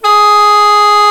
THAI PIPES04.wav